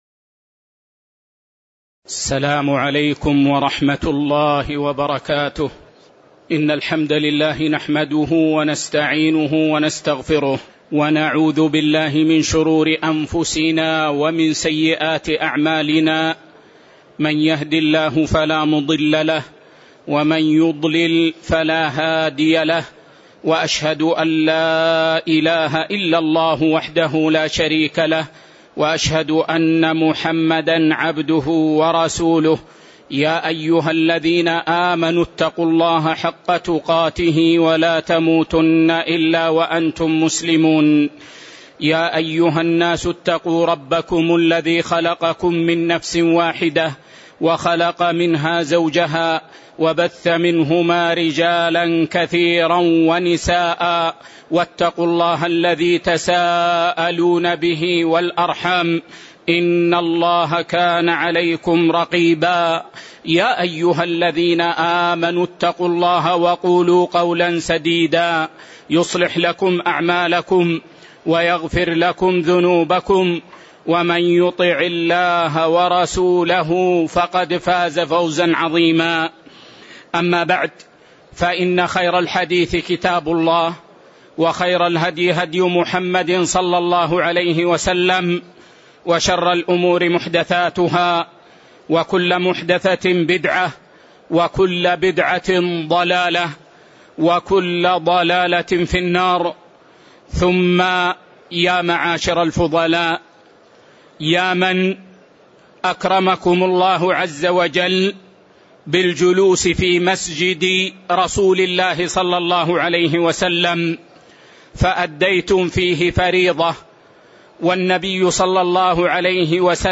تاريخ النشر ١٥ ذو الحجة ١٤٣٨ هـ المكان: المسجد النبوي الشيخ